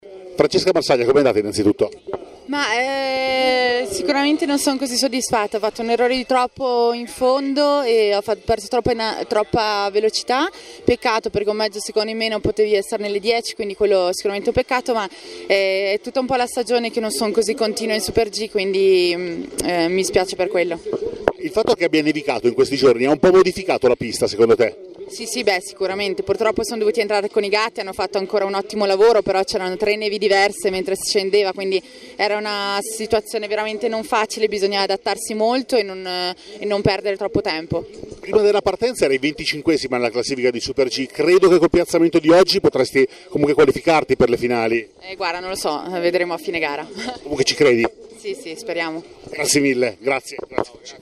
Intervista audio con Francesca Marsaglia
la-thuile-2020-super-g-francesca-marsaglia.mp3